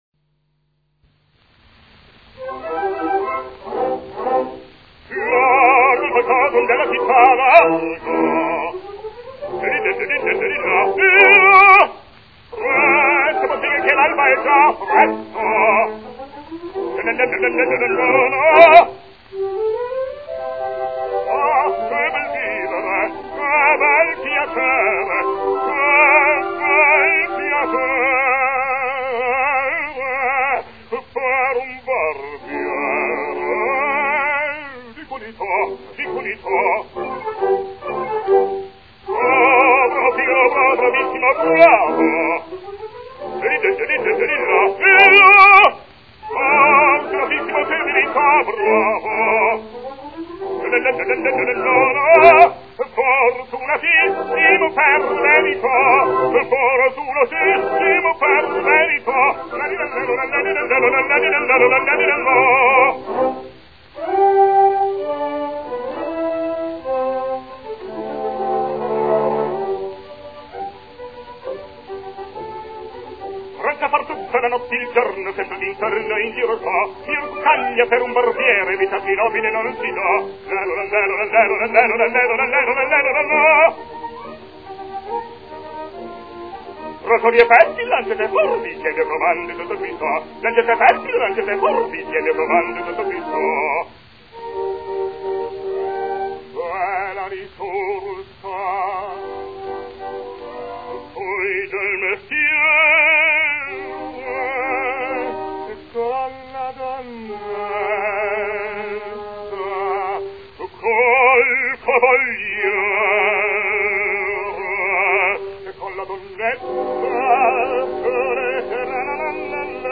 Italian baritone, 1875 - 1955